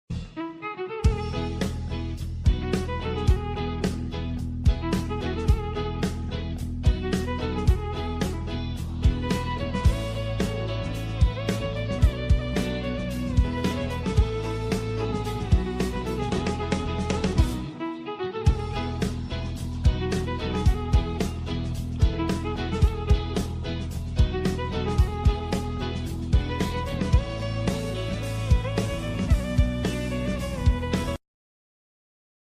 Every call unleashes the primal rhythm of the wild.